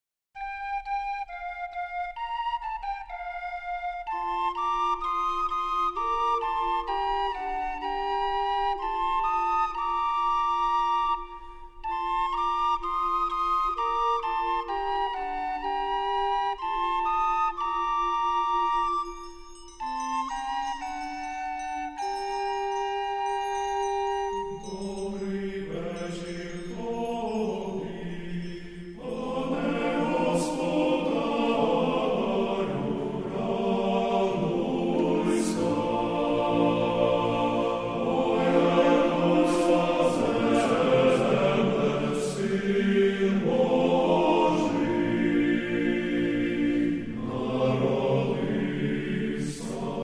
Різдвяні (94)